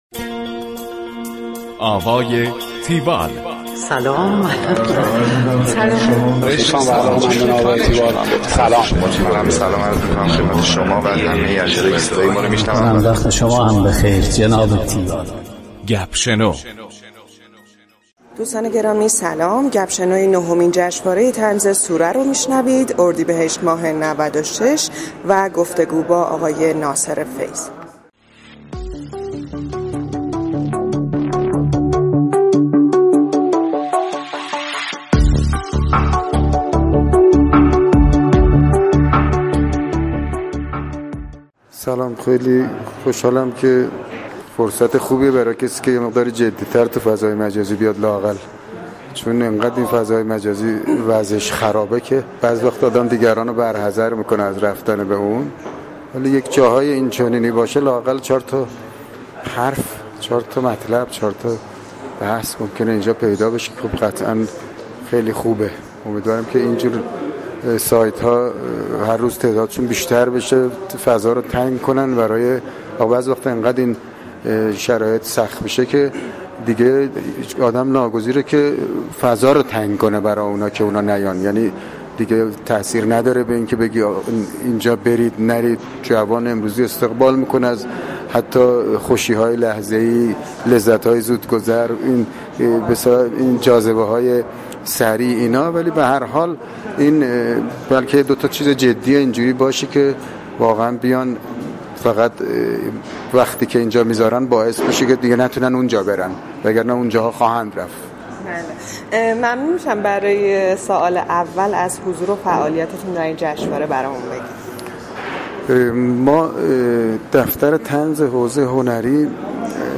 گفتگوی تیوال با ناصر فیض / طنزپرداز.
tiwall-interview-naserfeyz.mp3